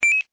游戏的声音" 水滴好1
描述：水滴打在纸上。
标签： 水滴 水滴
声道立体声